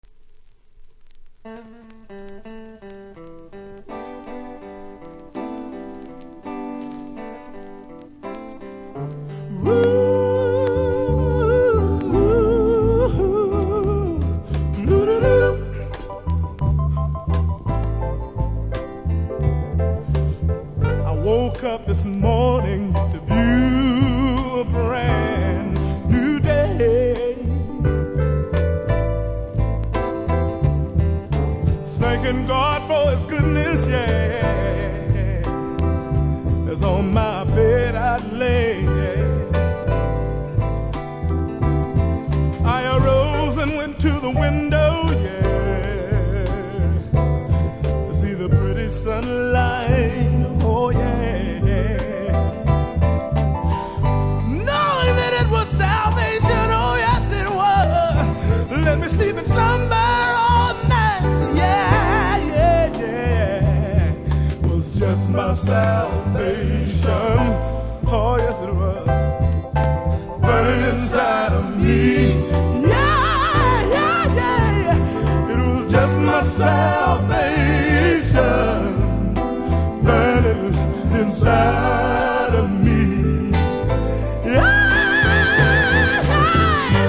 多少ノイズ感じますので試聴で確認下さい。